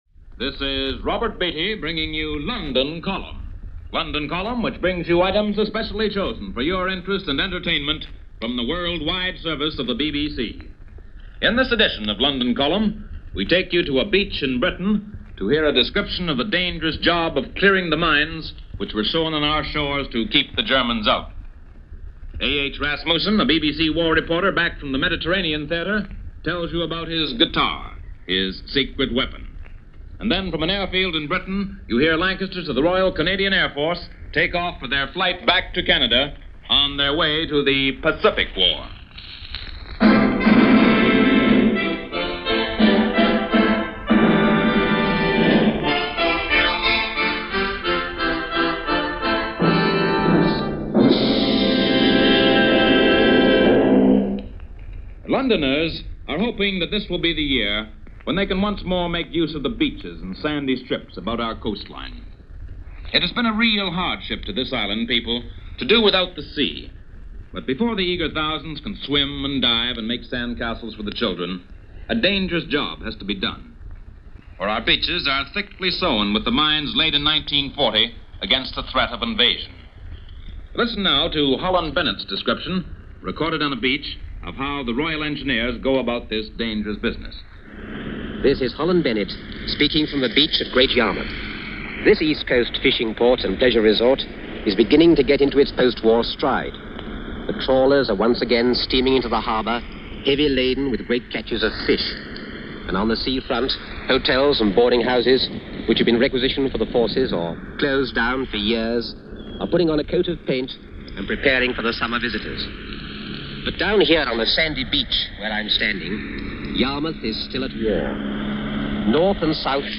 Report and Commentary on life in London - June 1945
A weekly radio feature was broadcast by the BBC World Service, called London Column which reported on the goings on in Britain and throughout Europe during the war. This installment features mine-clearing from English beaches and the departing of Royal Canadian Air Force planes, back to Canada and possibly over to aid in the Pacific conflict in the coming weeks.